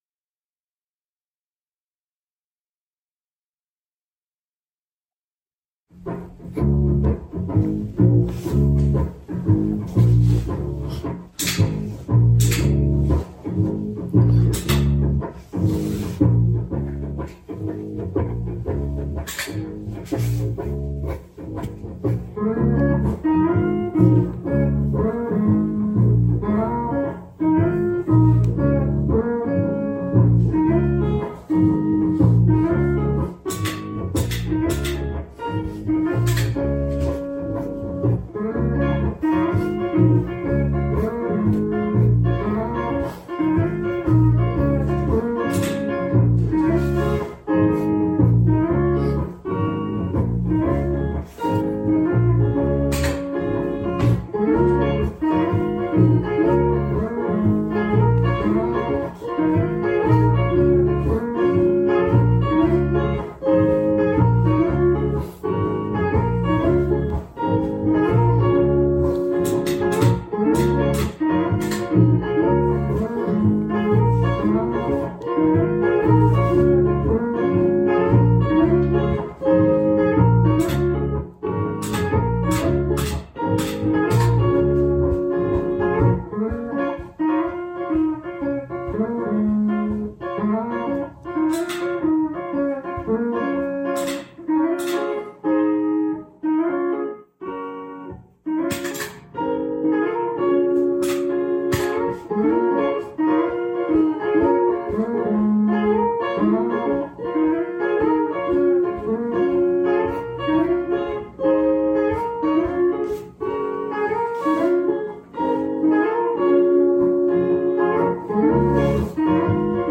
This is my first loop, other than the sound of a bunch of random buttons being pressed when I first hooked everything up.
I think this song came out so fun!
I love the groove!